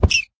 minecraft / sounds / mob / rabbit / hurt4.ogg